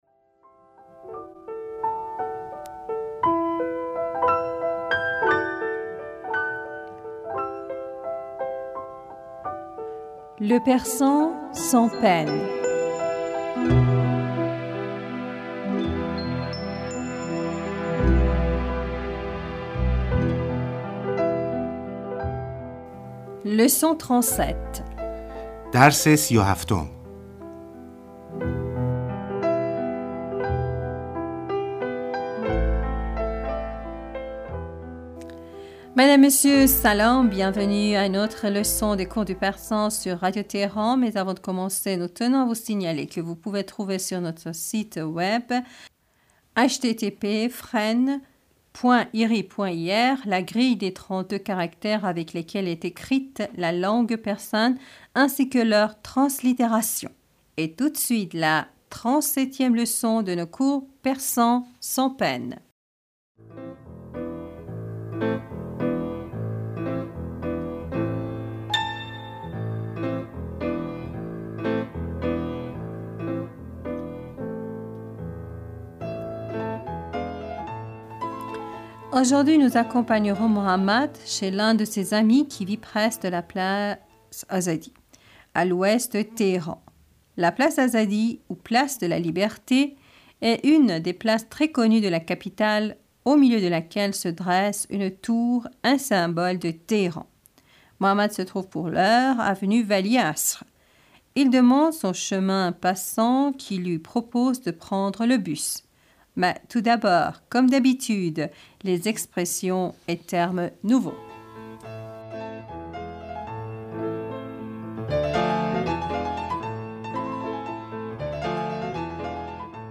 Bienvenus à une autre leçon des cours de persan sur Radio Téhéran.